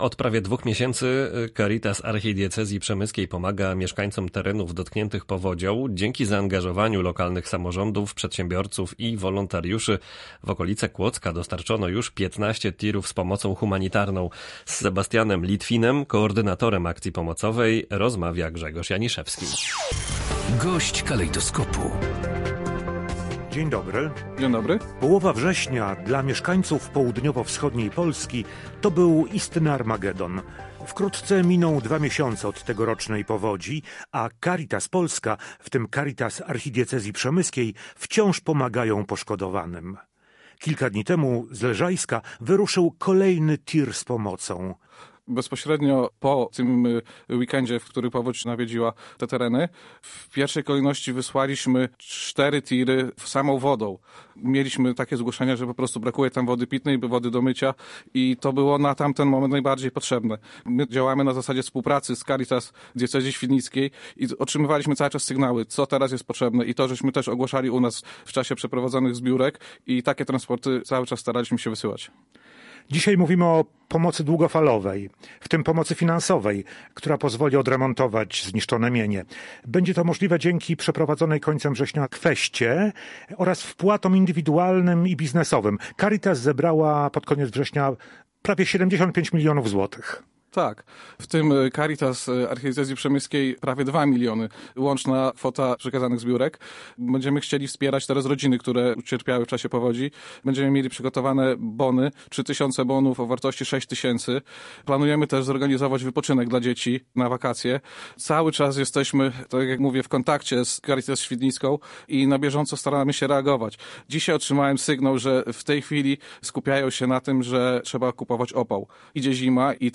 Audycje • Taka pomoc, jak mówił gość Polskiego Radia Rzeszów, nie byłaby możliwa, gdyby nie zaangażowanie społeczeństwa.